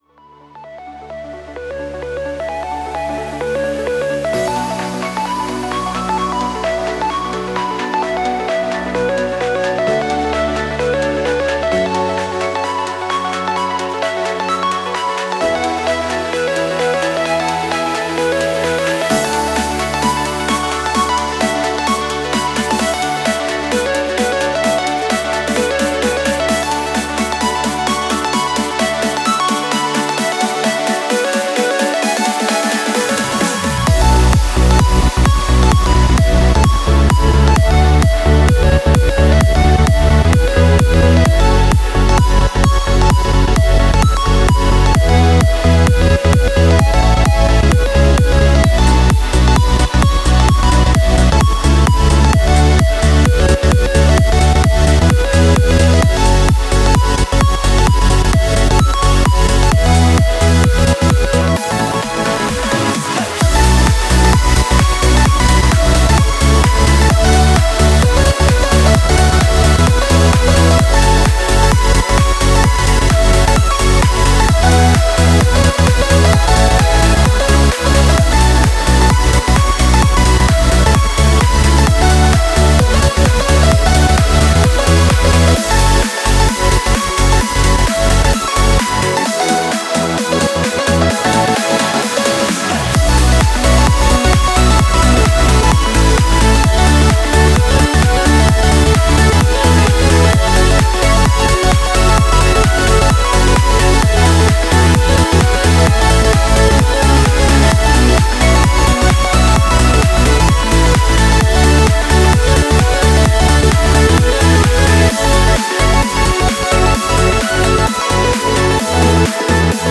Music / Trance